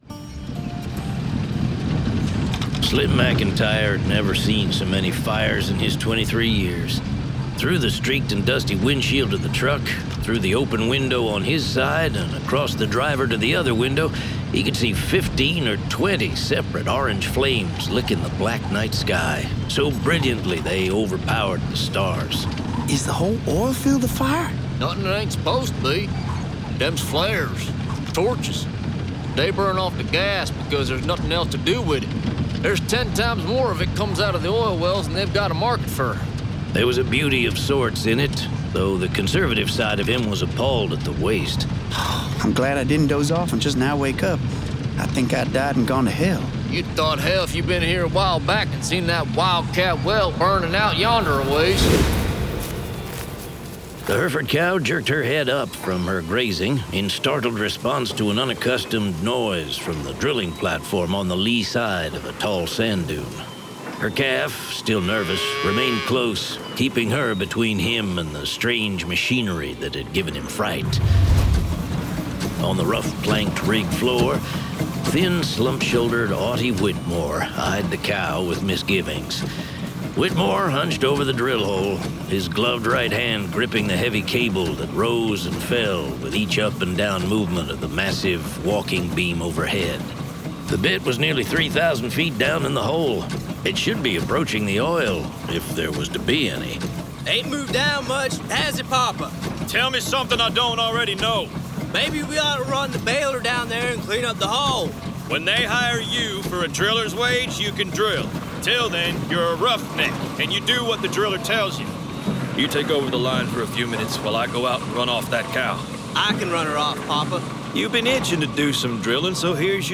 Honor at Daybreak [Dramatized Adaptation]
Full Cast. Cinematic Music. Sound Effects.
Genre: Western